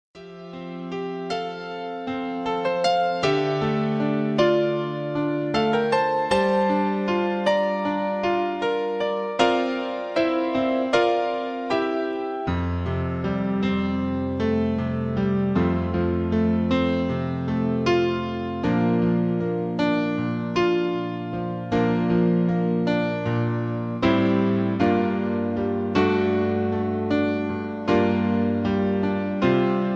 backing tracks
love songs, easy listening, ballards